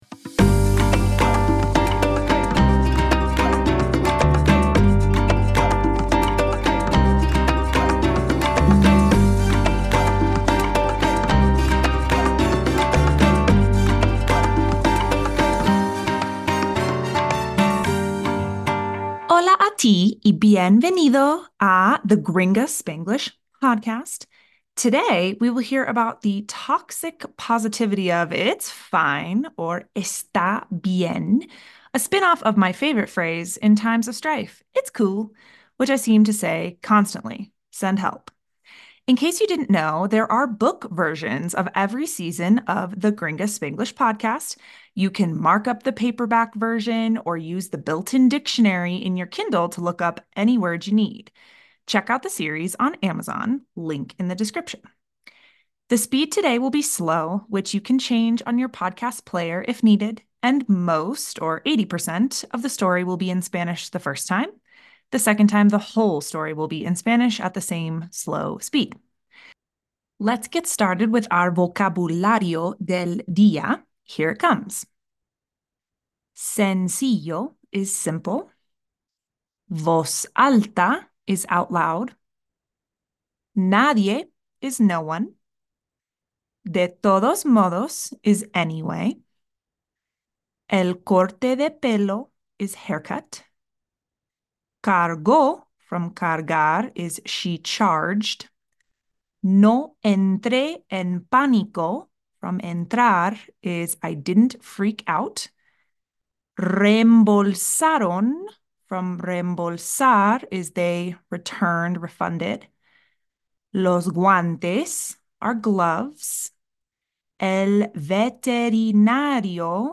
S03 E09 - Está bien - Slow Speed - 80% Spanish